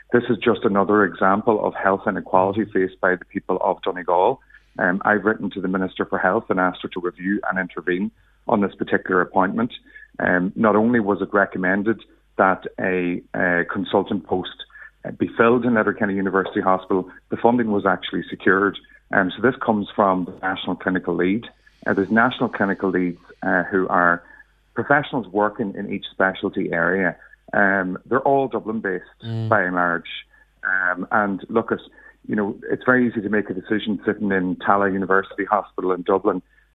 He says it’s another example of health discrimination in Donegal: